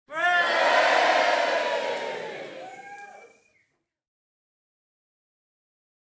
fccheer.ogg